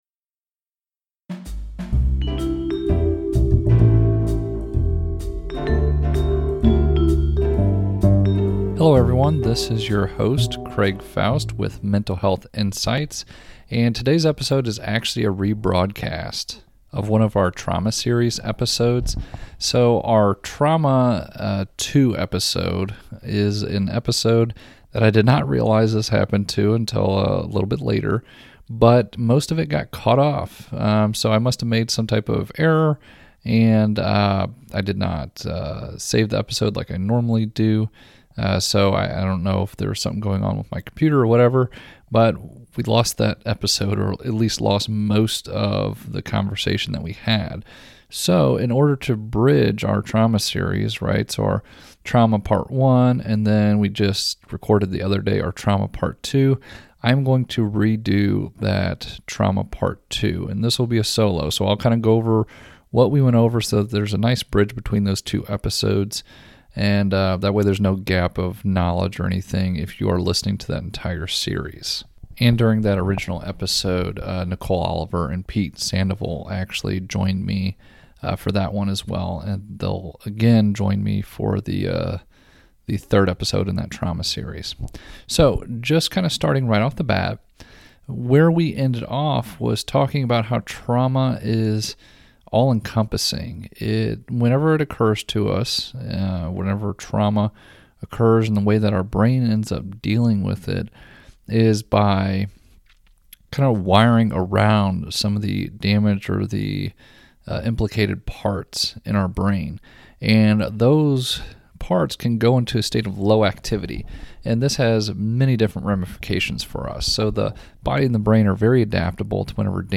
Due to an error with the the original episode, a good portion was cut out. This episode is a solo episode to go over some of the key areas we covered to make sure the information is still available and to help with a smooth transition to the next trauma episode in the series!